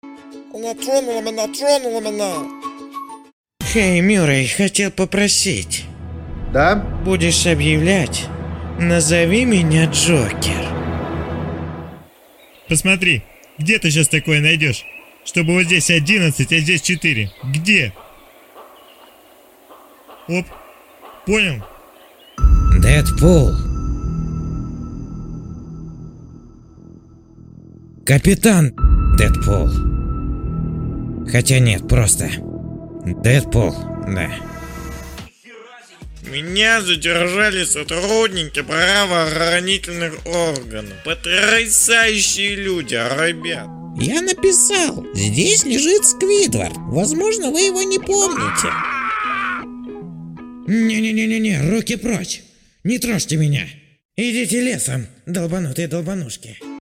Муж, Рекламный ролик
Звуковая карта focusrite solo 3rd, микрофон se electronics x1 s